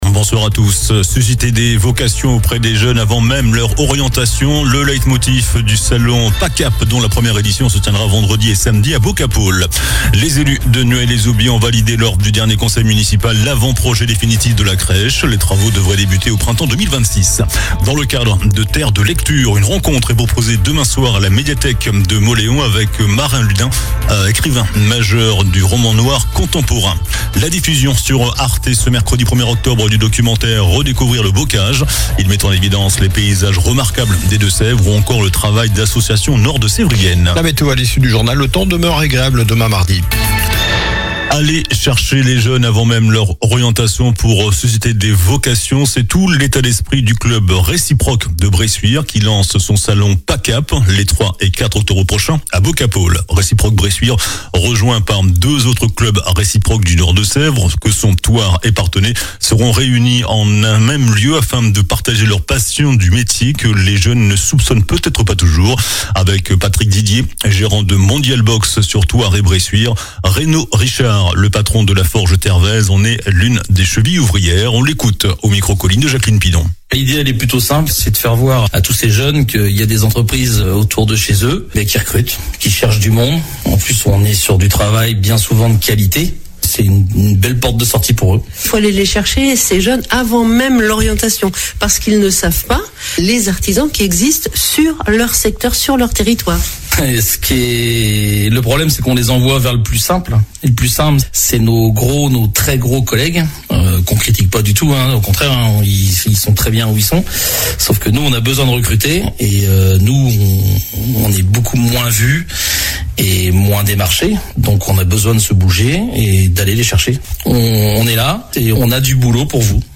JOURNAL DU LUNDI 29 SEPTEMBRE ( SOIR )